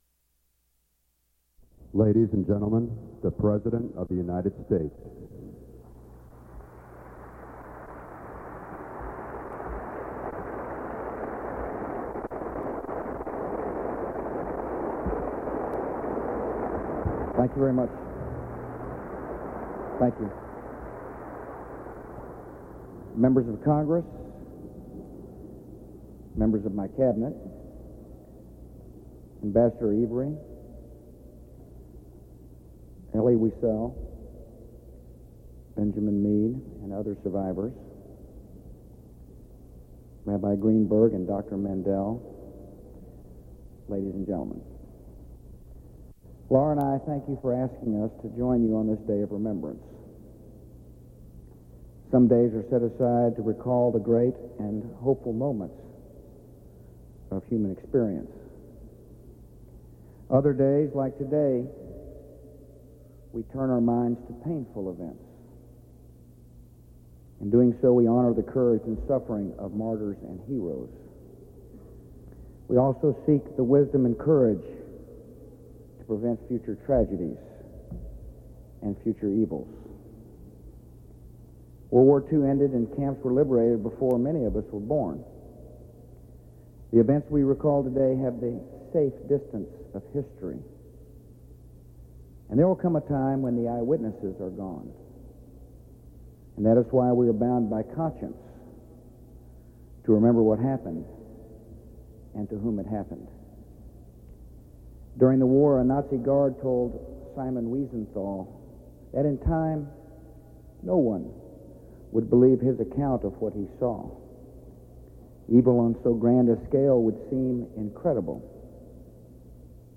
U.S. President George W. Bush speaks at the Days of Remembrance ceremony